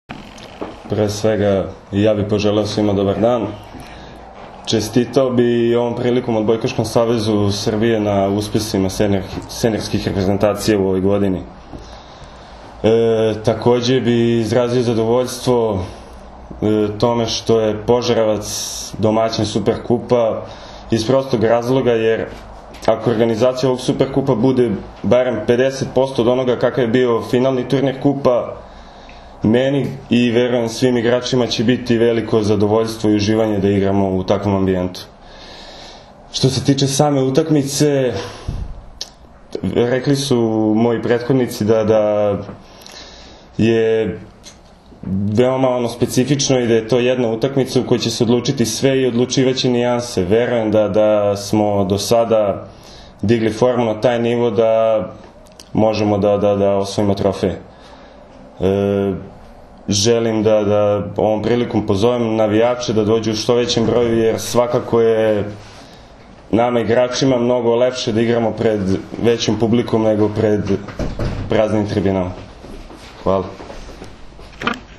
U prostorijama Odbojkaškog saveza Srbije danas je održana konferencija za novinare povodom utakmice VI Super Kupa Srbije 2016, koja će se odigrati sutra (četvrtak, 6. oktobar) od 18,00 časova u dvorani SC “Požarevac” u Požarevcu, između Crvene zvezde i Mladog radnika iz Požarevca, uz direktan prenos na RTS 2.
IZJAVA